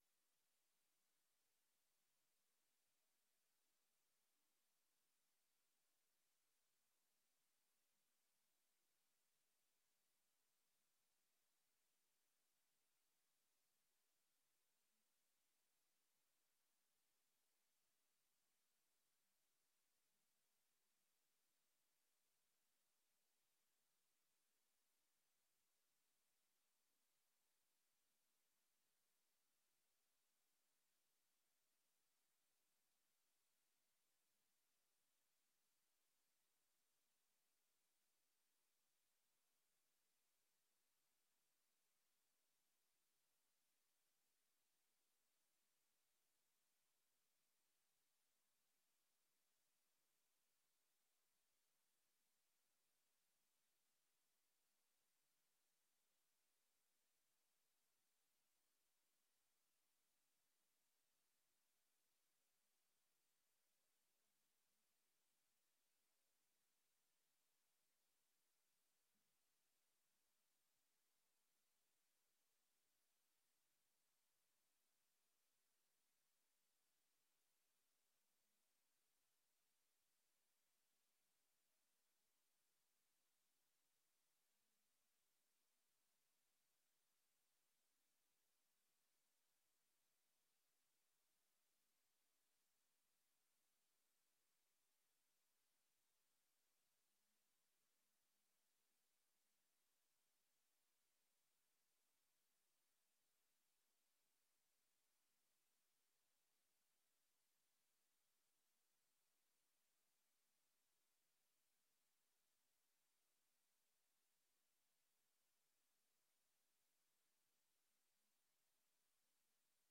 Gemeenteraad 12 september 2022 19:30:00, Gemeente Den Helder
Download de volledige audio van deze vergadering